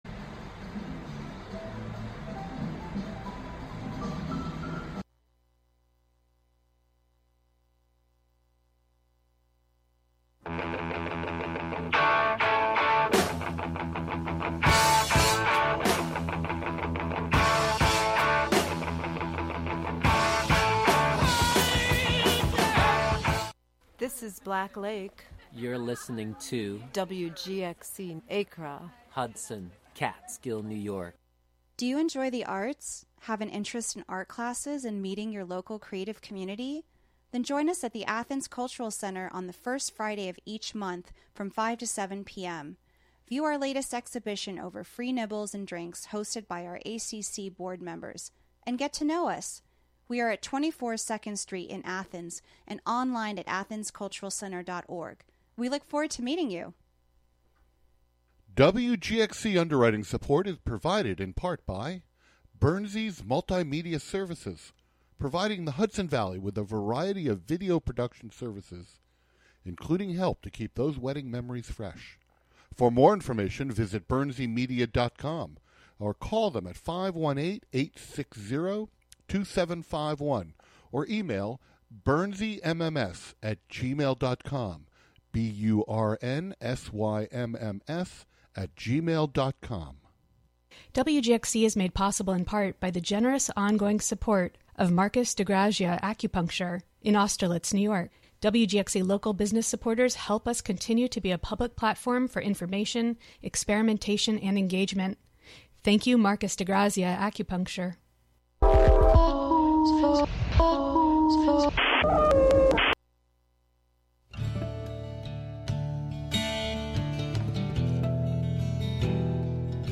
7pm Guest: Phil Manzanera Phil Manzanera is a guitar...
Expect lively conversation and a playlist of great music to go with it.